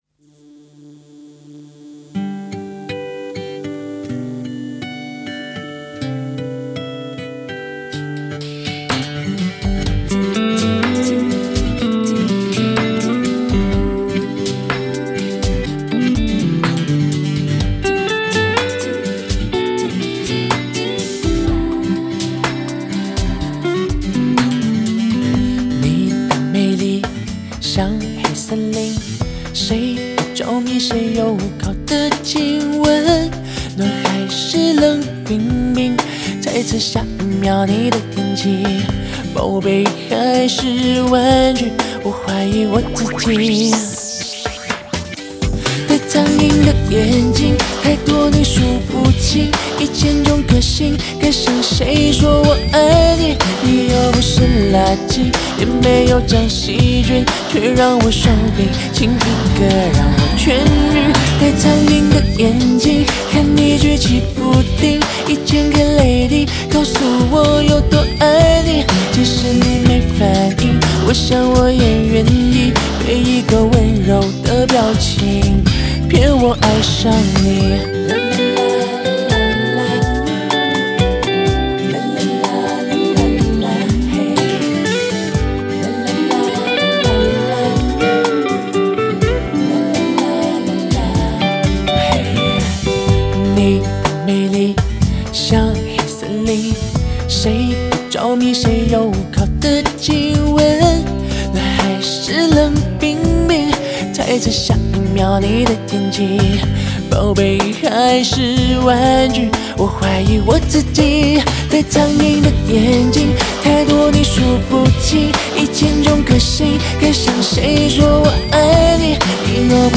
节奏民谣
明快的吉他和玄配上贴切的生活语言，是一首校园风的情歌创作。
轻快校园式的节奏民谣曲风